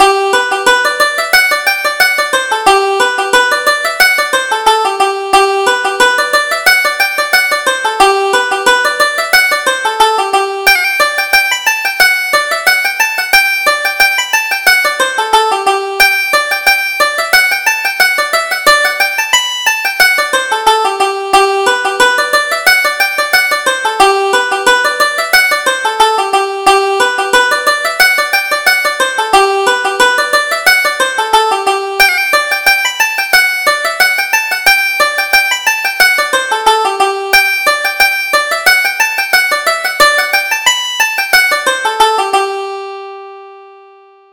Reel: Sweet Biddy of Ballyvourney